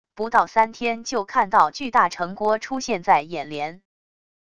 不到三天就看到巨大城郭出现在眼帘wav音频生成系统WAV Audio Player